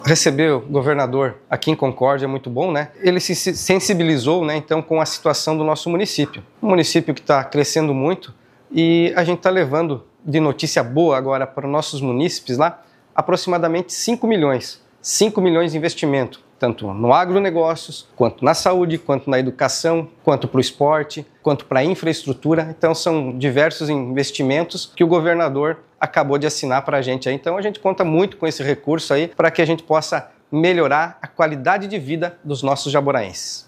Após a conversa individual com o governador Jorginho Mello, o prefeito de Jaborá, Clevson Rodrigo Freitas, destacou investimento no agronegócio, saúde, educação, infraestrutura e esporte: